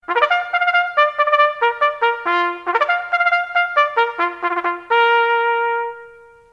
دانلود آهنگ زنگ 10 از افکت صوتی اشیاء
جلوه های صوتی
دانلود صدای زنگ 10 از ساعد نیوز با لینک مستقیم و کیفیت بالا
برچسب: دانلود آهنگ های افکت صوتی اشیاء دانلود آلبوم صدای زنگ هشدار از افکت صوتی اشیاء